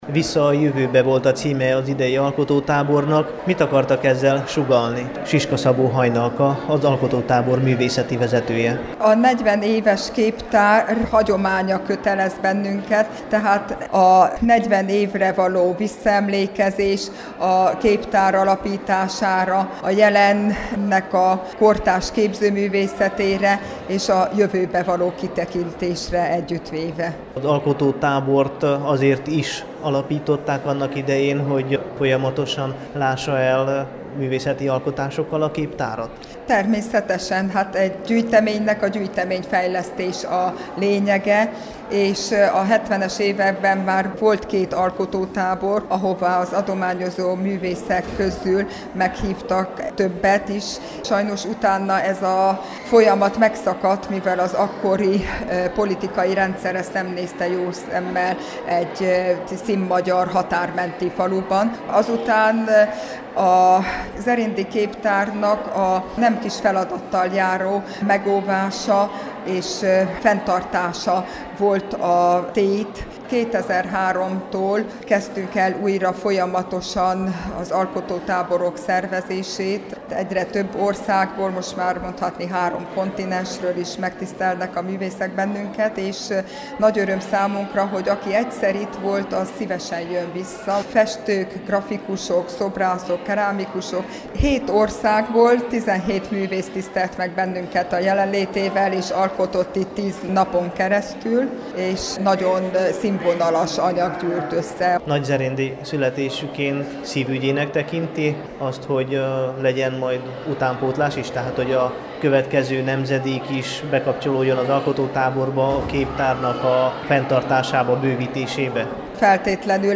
A vasárnap esti záró kiállításon és ünnepségen készült